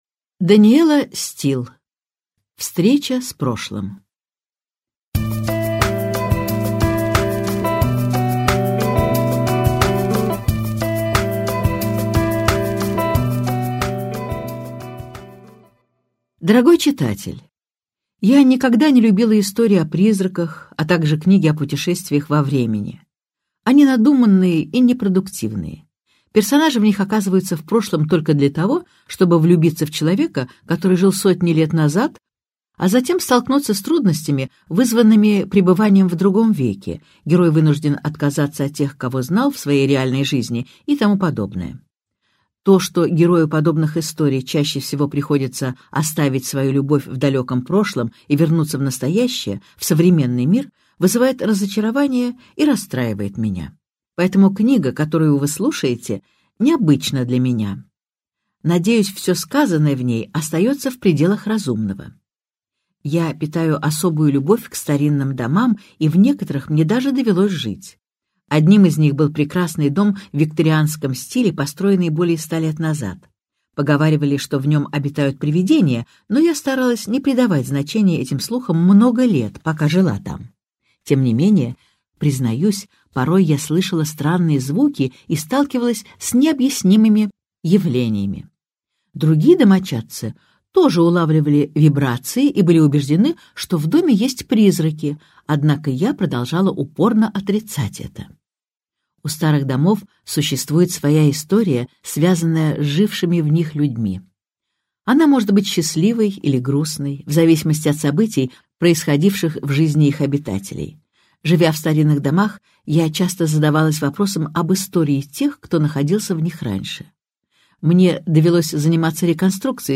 Аудиокнига Встреча с прошлым | Библиотека аудиокниг